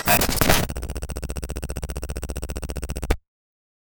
Rétrofuturiste, science fiction anime japonais année 80-90. 0:10 Retro terminal boot: CRT pop and coil whine, gritty hum, rapid teletype-like mechanical clicks, dense floppy/HDD chatter, layered static bursts. Feels like the machine is typing itself awake, industrial and tactile, Ghost in the Shell aesthetic. 0:04
retro-terminal-boot-crt-p-n7hdp6tk.wav